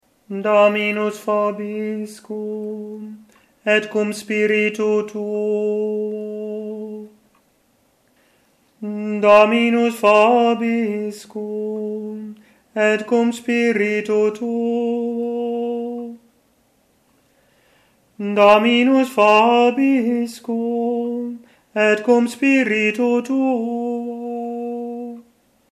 Gregoriaans
Precies op die plaatsen waar je ook bij het gewone voorlezen je stem verheft, zongen zij een toontje hoger. Of ze zongen de eindnoot wat lager, net zoals je stem daalt op het einde van een zin.